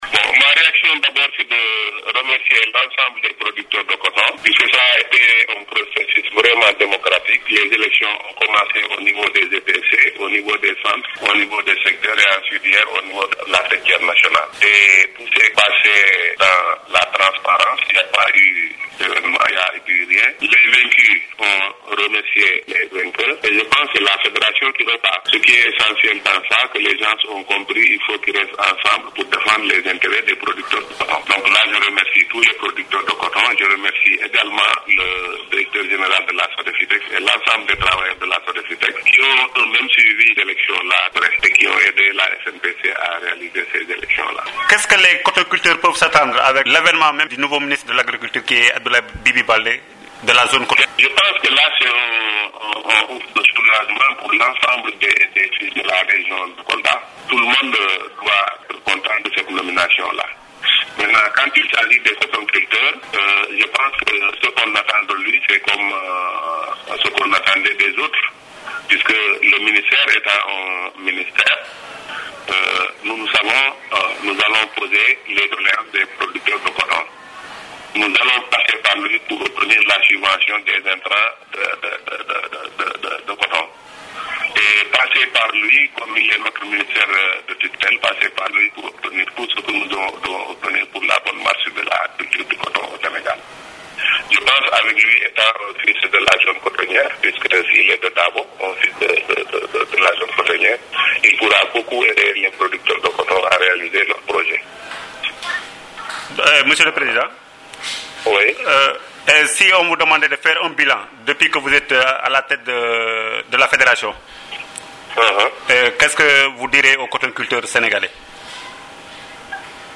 au téléphone